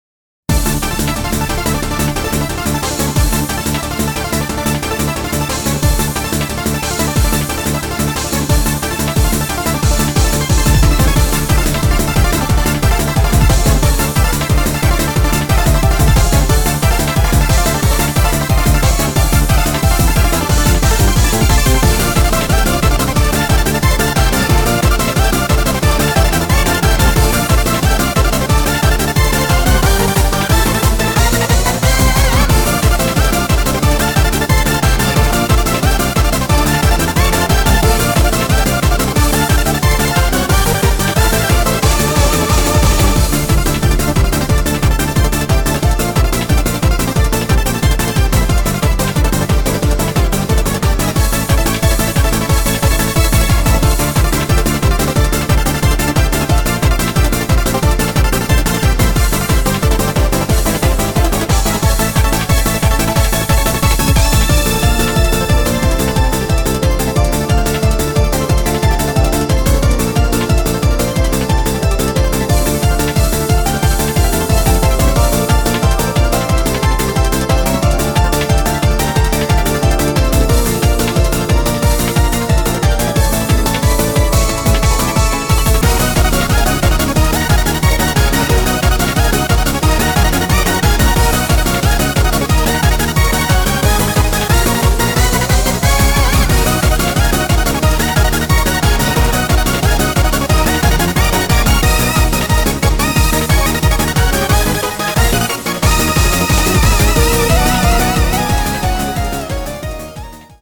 TypePad[Video Game Remix
Audio QualityPerfect (High Quality)